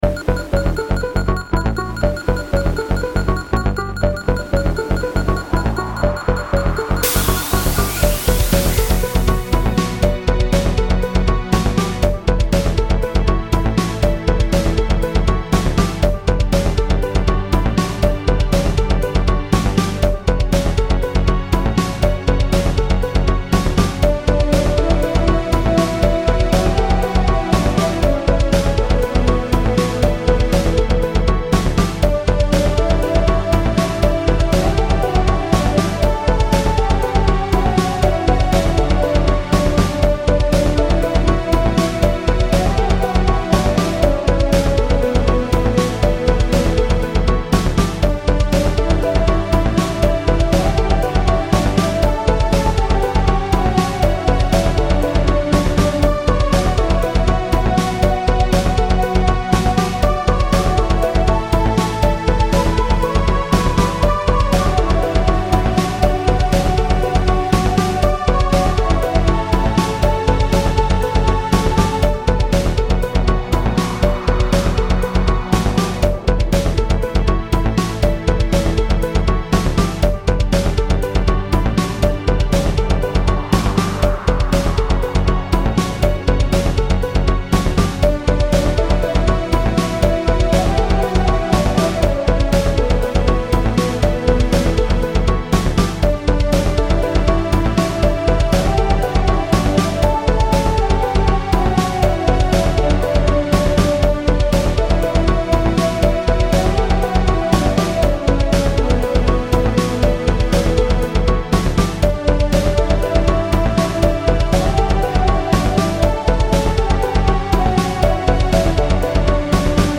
Beta Cyberpunk RPG BGM